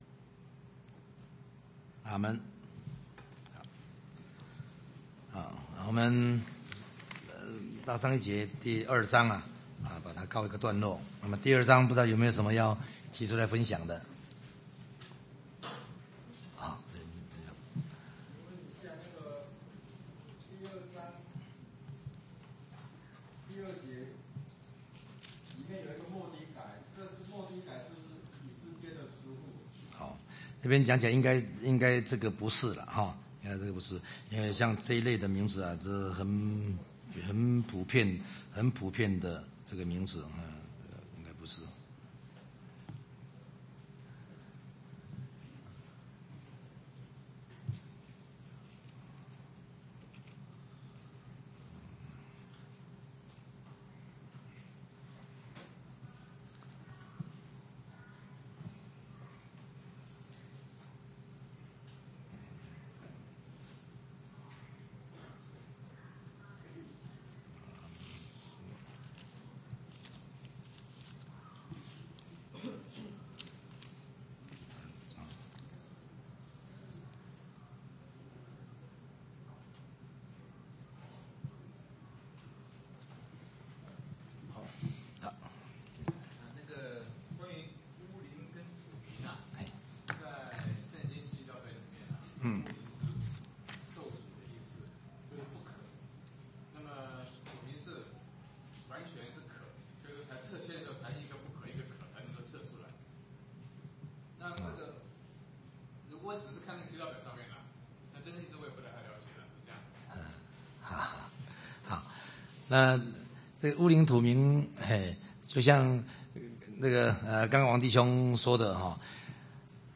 講習會
地點 台灣總會 日期 08/10/2008 檔案下載 列印本頁 分享好友 意見反應 Series more » • 以斯拉記 11-1 • 以斯拉記 11-2 • 以斯拉記 11-3 …